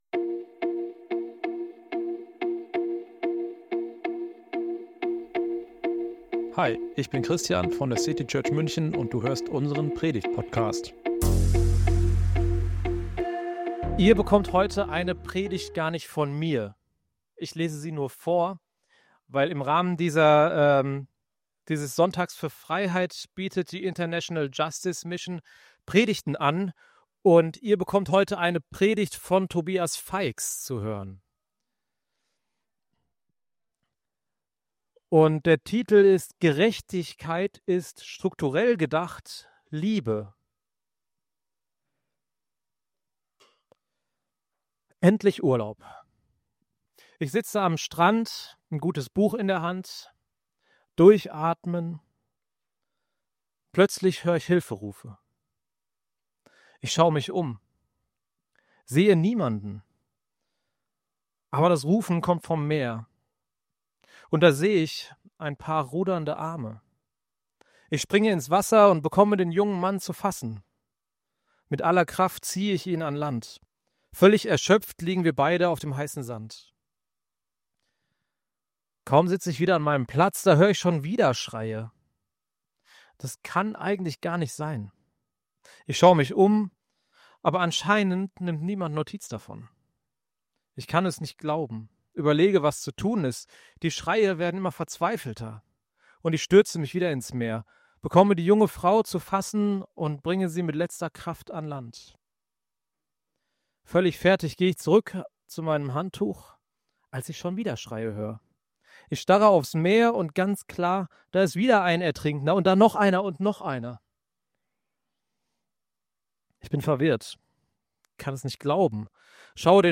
In dieser Predigt werden wir einen Einblick in ihre...
Die International Justice Mission setzt sich dafür ein, dass Menschen die Freiheit geschenkt wird. In dieser Predigt werden wir einen Einblick in ihre Arbeit bekommen.